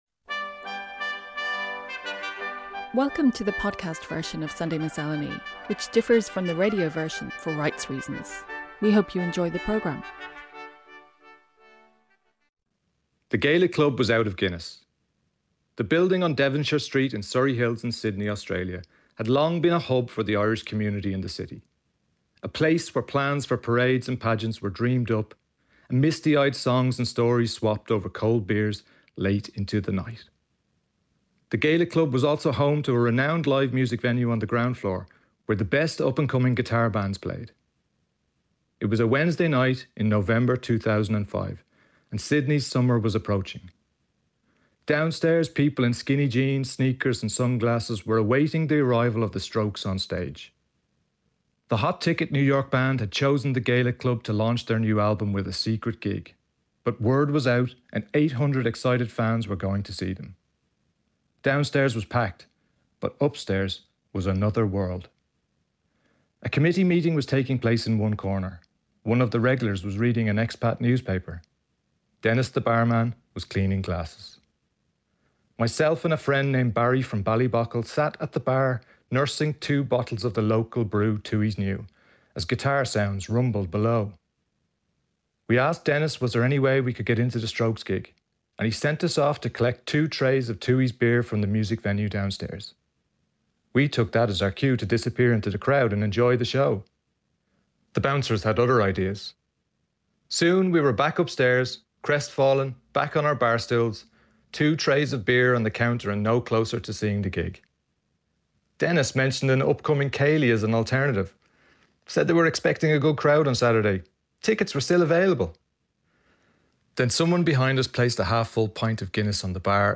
Radio essays, poems and complementary music, broadcast from Ireland on RTÉ Radio 1, Sunday mornings since 1968.